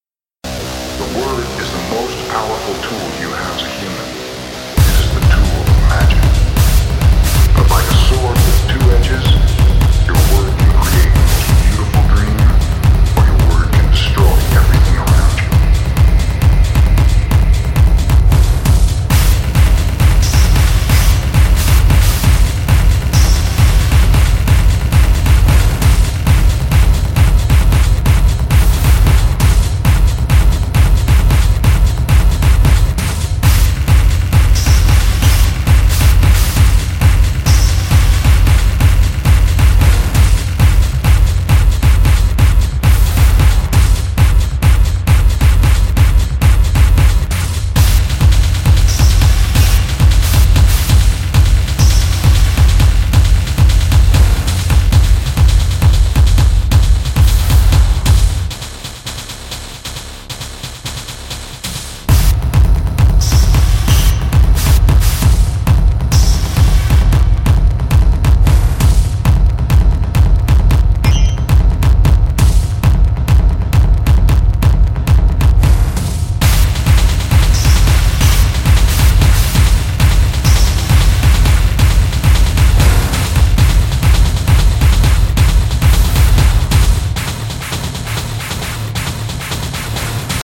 Fierce banging techno tracks!